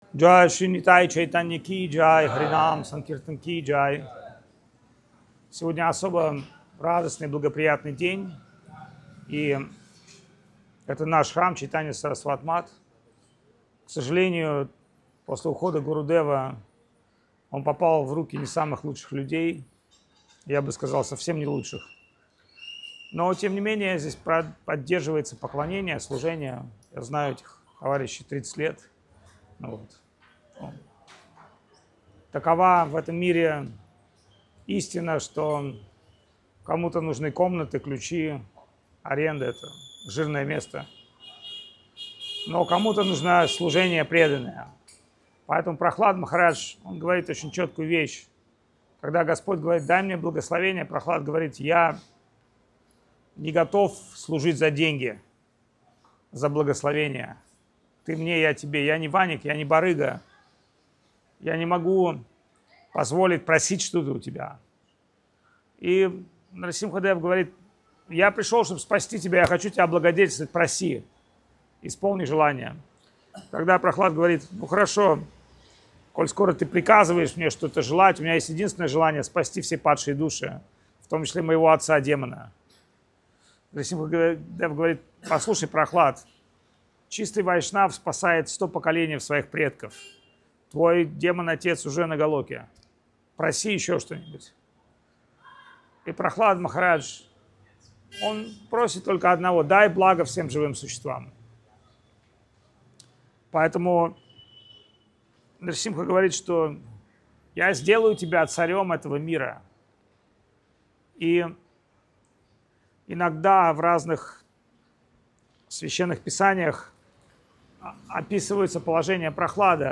Фрагменты лекций